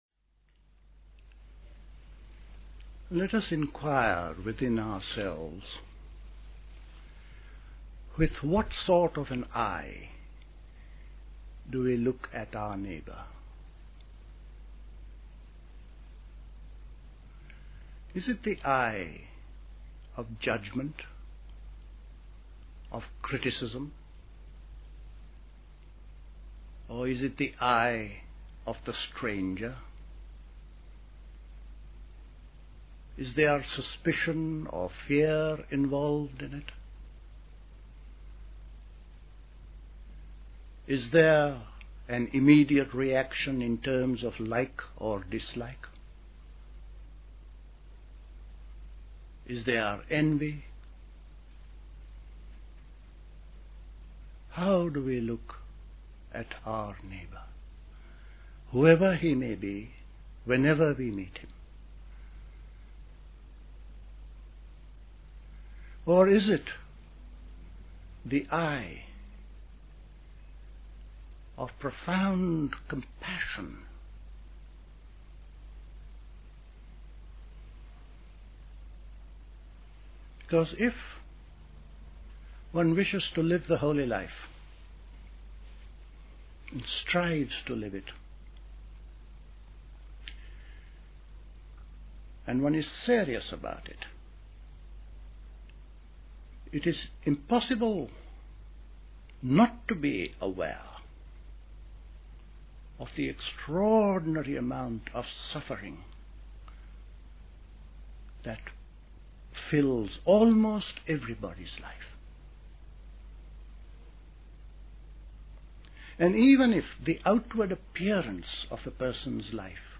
A talk
at Dilkusha, Forest Hill, London on 12th September 1971